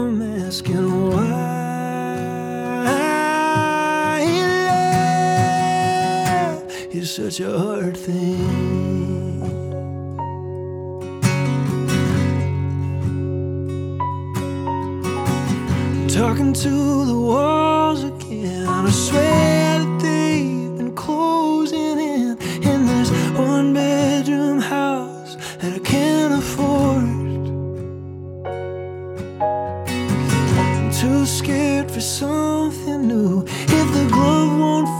Жанр: Фолк-рок
# Folk